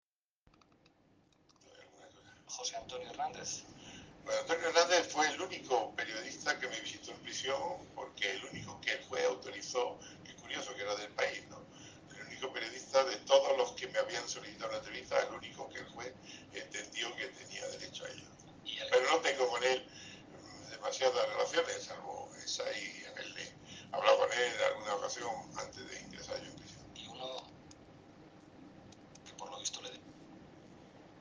La entrevista entre Iglesias y Villarejo será objeto de análisis y discusión en la esfera pública española durante estos días.
Careo en RAC1